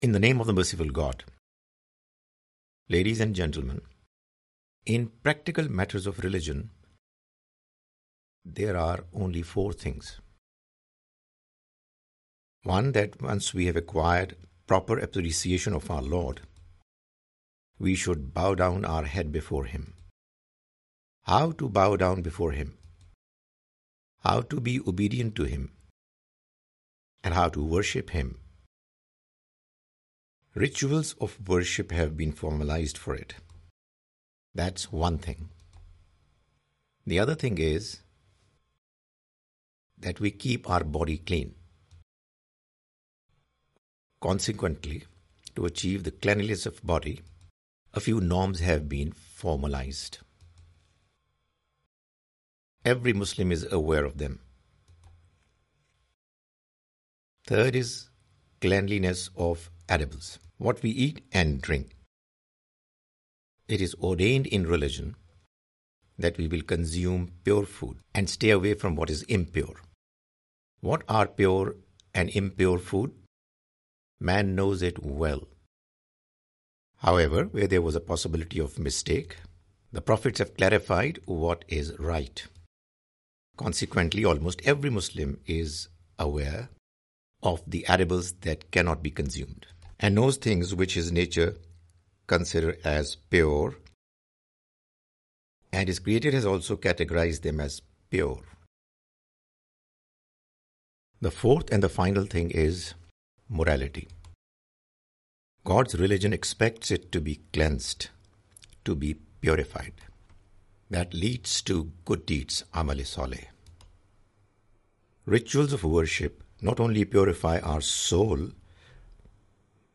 The Message of Qur'an (With English Voice Over) Part-6
The Message of the Quran is a lecture series comprising Urdu lectures of Mr Javed Ahmad Ghamidi.